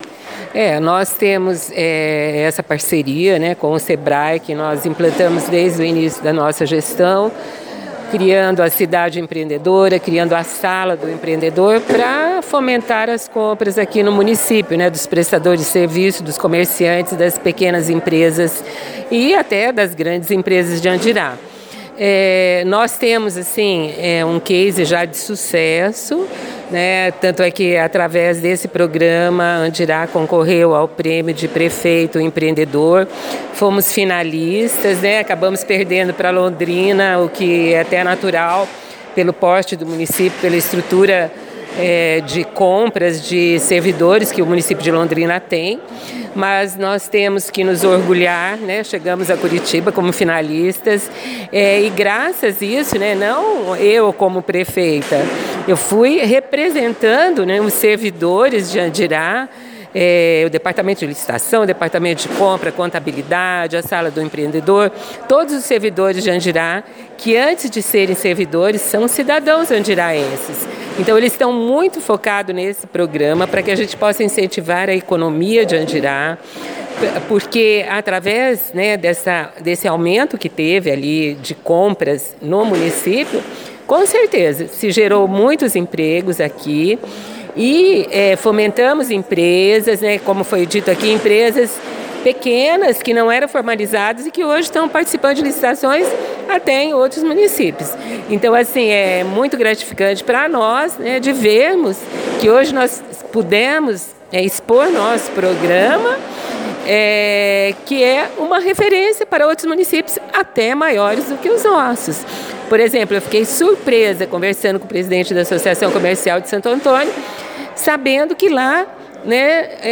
O Seminário Regional de Compras Públicas, realizado no município de Andirá, na última quarta-feira (7), reuniu representantes dos setores administrativos (em especial a área de compras) de dezessete prefeituras do Norte Pioneiro.